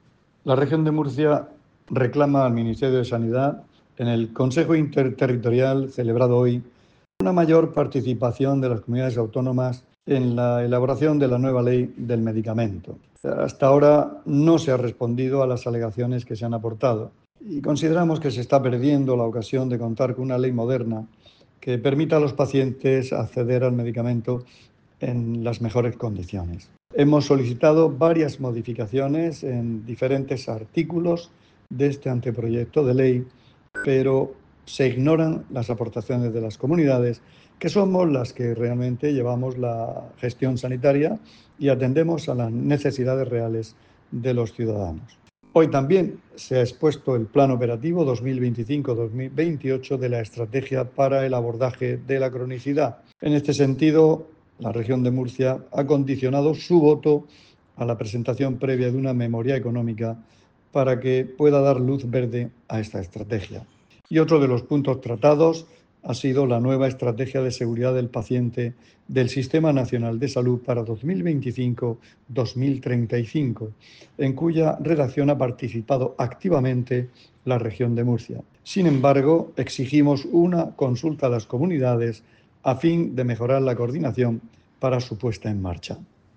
Sonido/ Declaraciones del consejero de Salud, Juan José Pedreño, sobre la reunión del Consejo Interterritorial del Sistema Nacional de Salud, celebrada hoy.